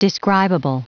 Prononciation du mot describable en anglais (fichier audio)
Prononciation du mot : describable